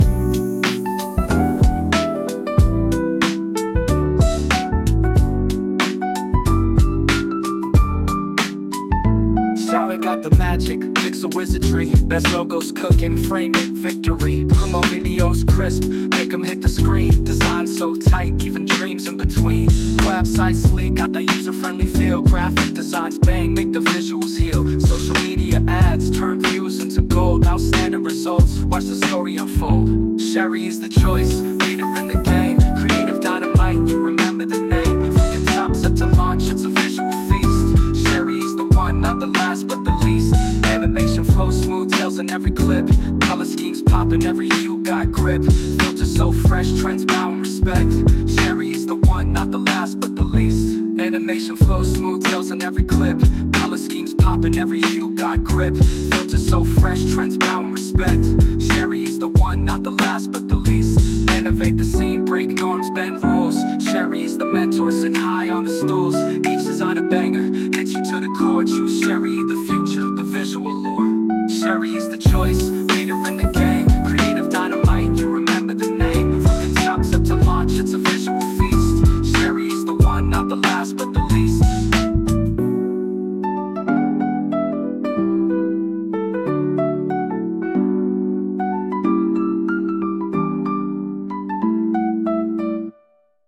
Music Genre: Jazz Pop Song (Male Singer)